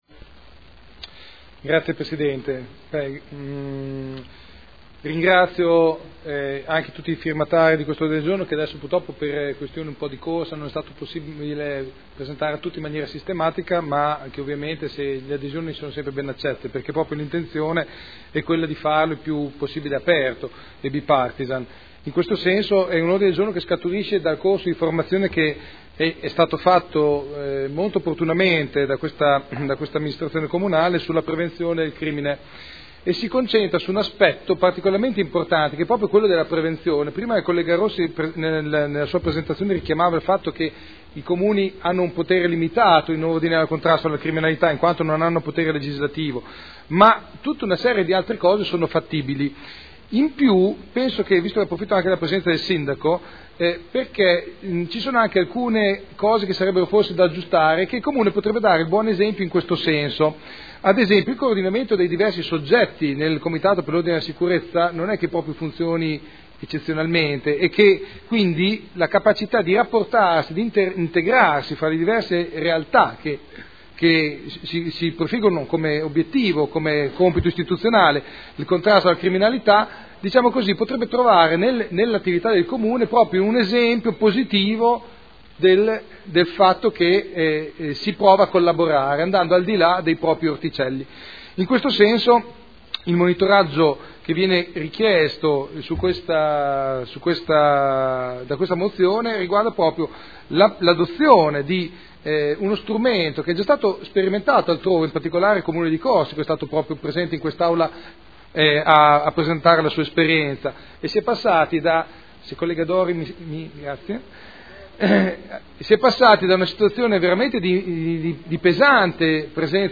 Seduta del 25/03/2013. Ordine del Giorno non iscritto presentato dal consigliere Artioli